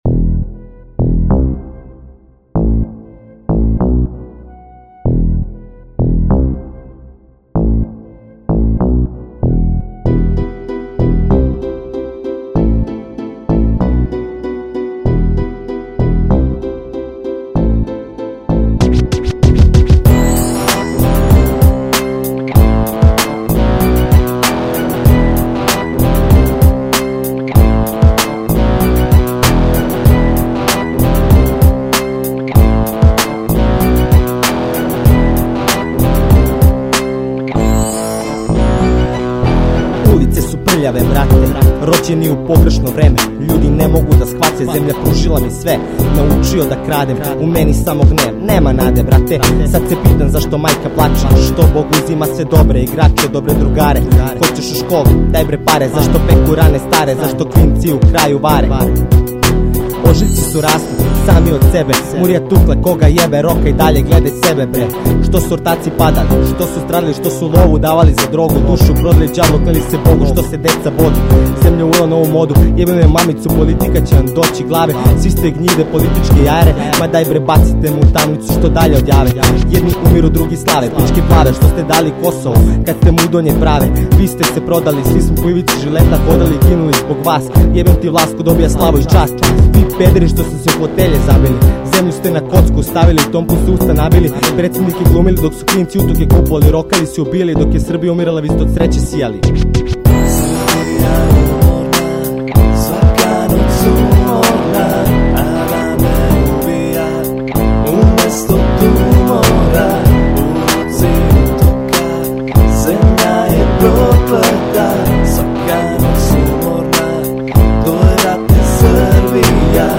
који је свирао гитару.
клавијатури